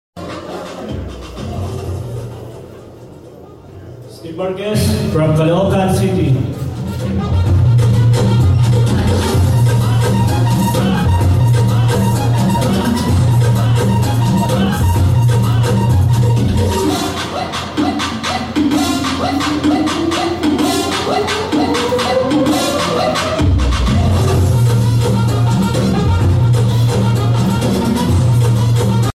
SELF INTRODUCTION